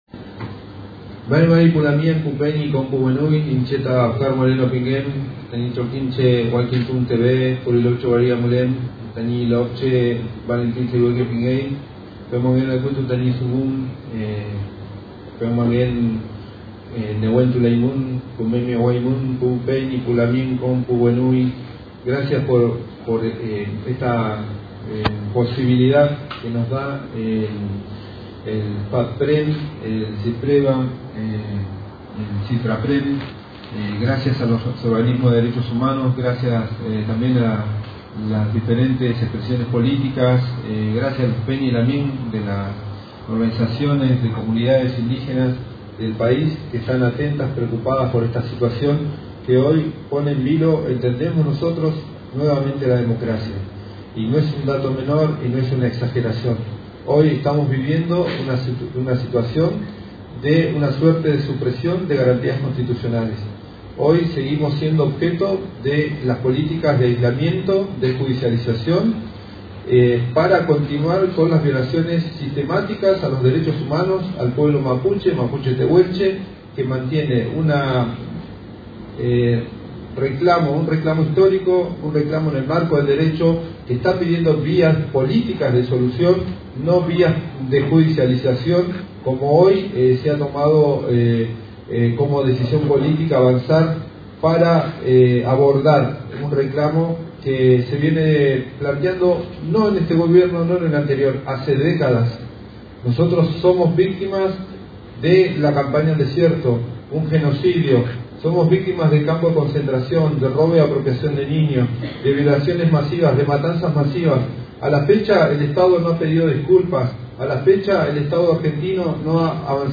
FATPREN llevó adelante una conferencia de prensa contra las amenazas y persecución a periodistas en Río Negro
Debido a las amenazas y persecuciones a periodistas de la provincia en el marco la represión al pueblo mapuche, la Federación Argentina de Trabajadores de Prensa oficializó hoy una conferencia de prensa. Escuchamos algunas de las voces participantes.